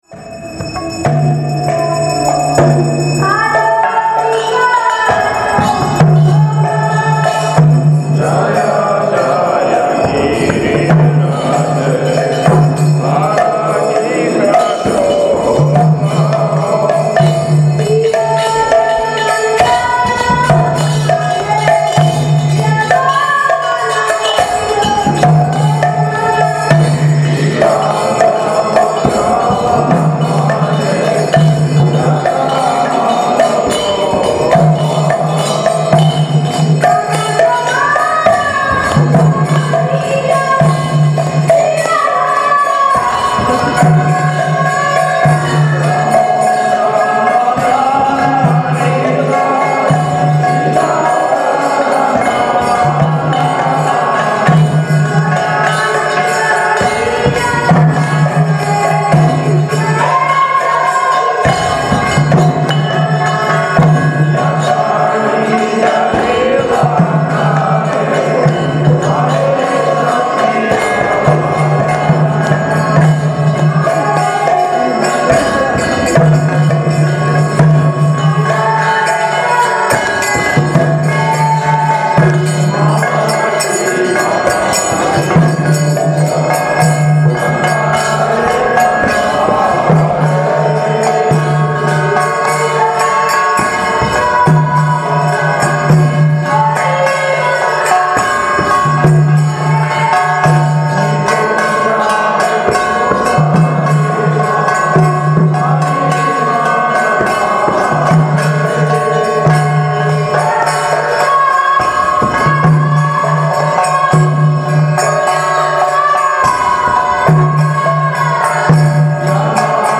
Place: Srila Sridhar Swami Seva Ashram Govardhan
Tags: Kirttan